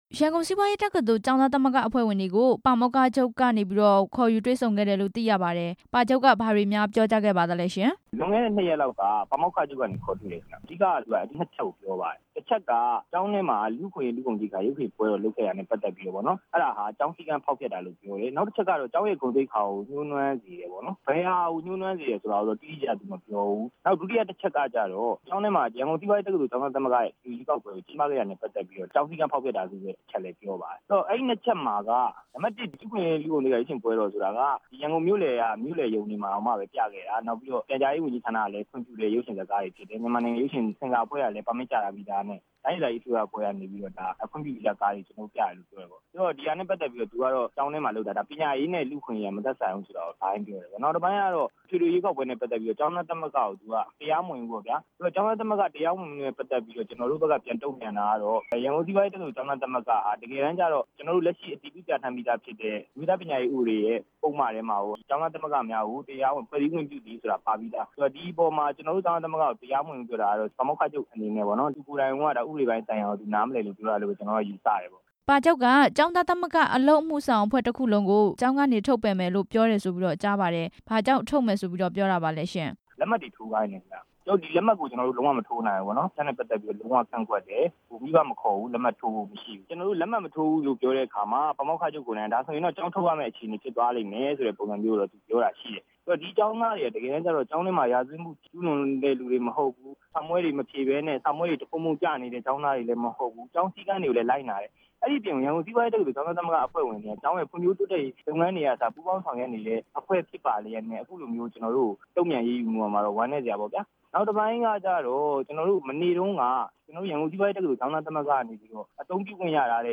ကိုစည်သူမောင်ကို မေးမြန်းချက်